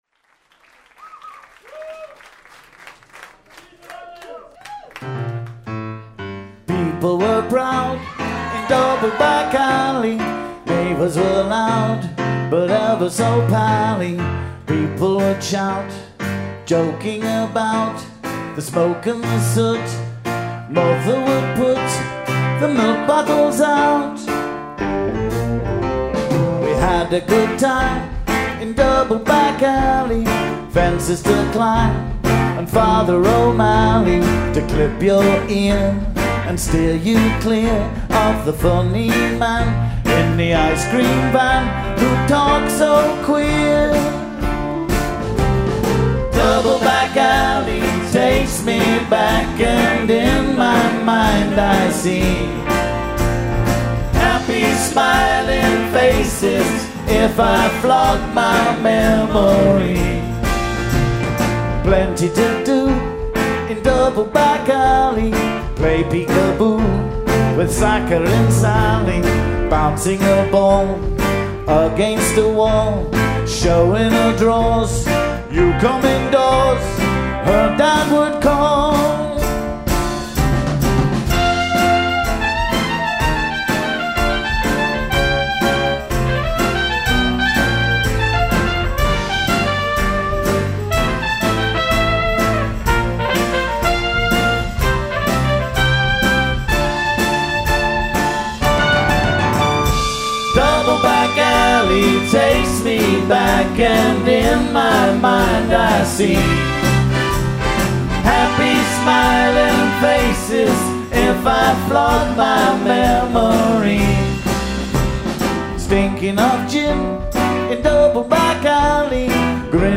Live @ Martyrs', Chicago